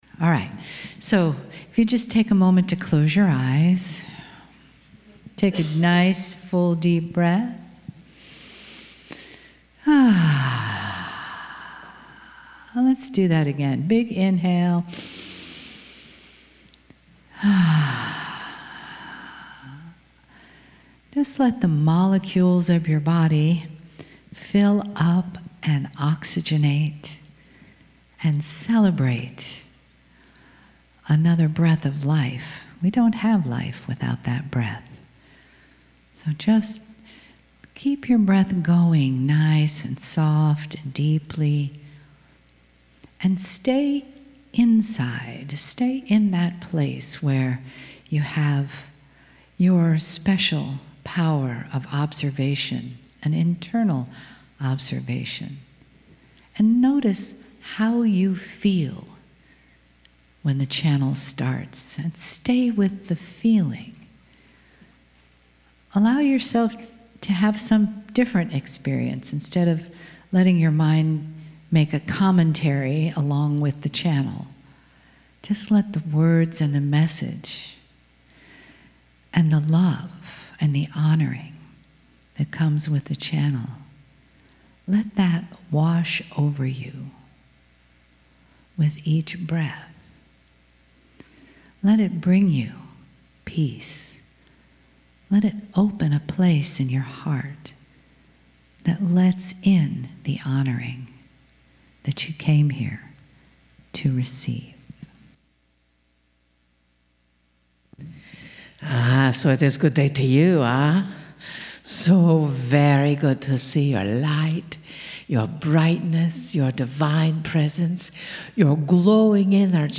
Kryon in Sarasota - January 18,19 2020
MULTI-CHANNELLING The Journey of the Human Soul - P1